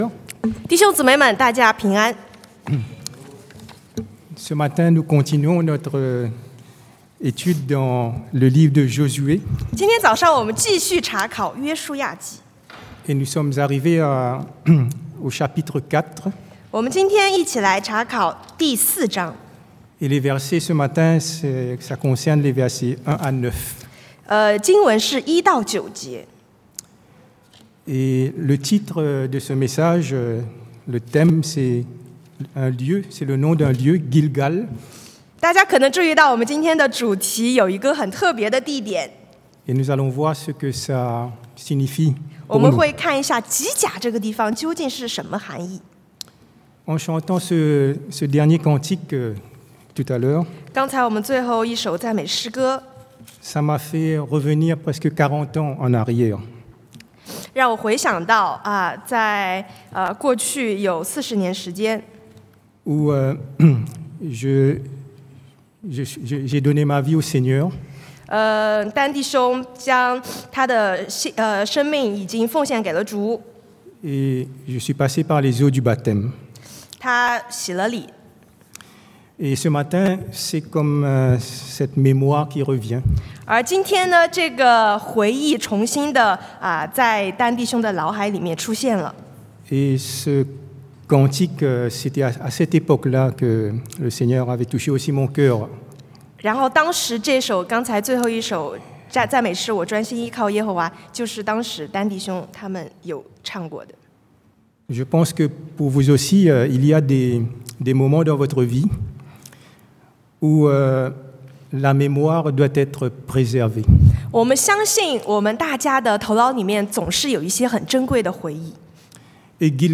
Passage: Josué 约书亚记 4 : 1-9 Type De Service: Predication du dimanche